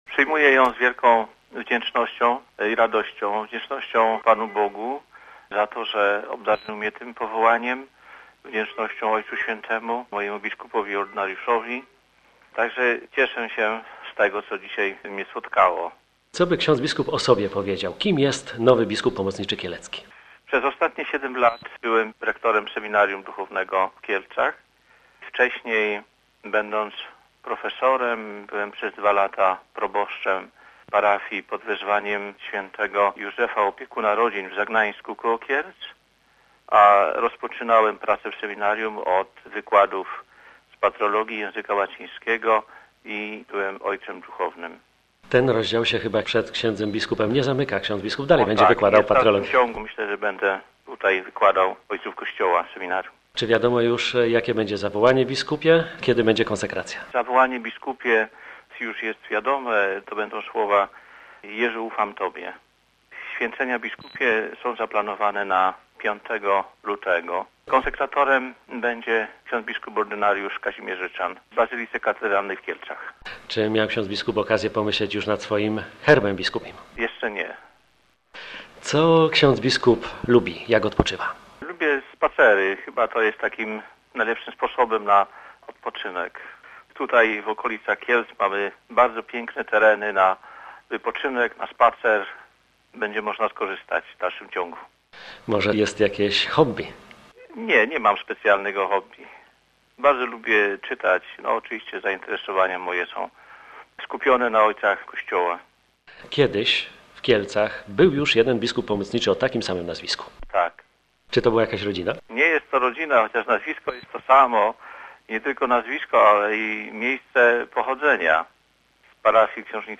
Zapytaliśmy go jak przyjął swoją nominację: